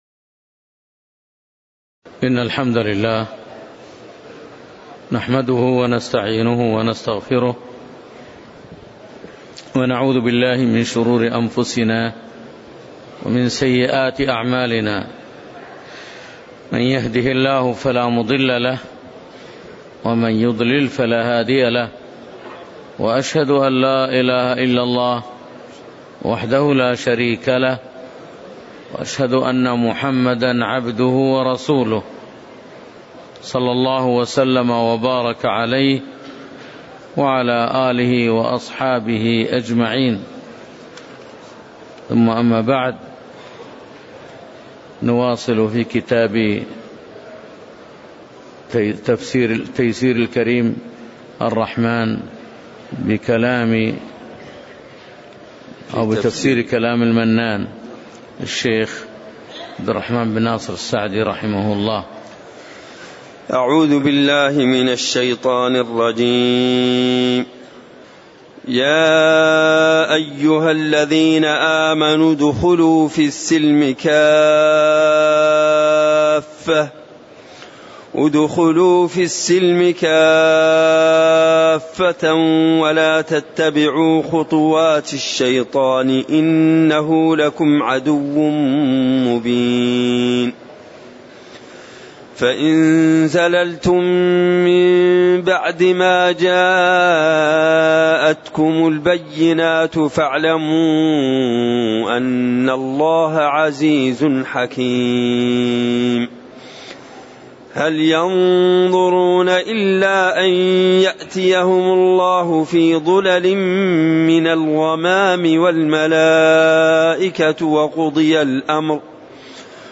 تاريخ النشر ١٨ ذو الحجة ١٤٣٨ هـ المكان: المسجد النبوي الشيخ